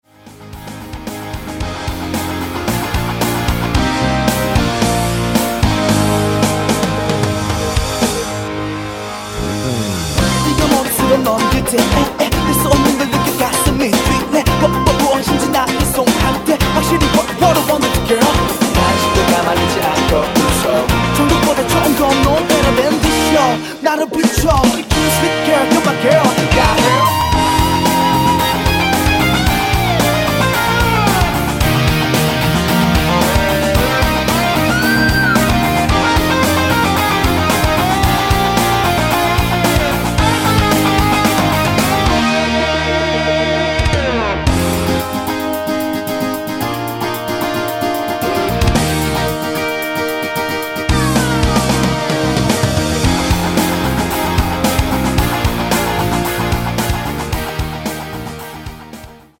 랩 포함된 MR 입니다.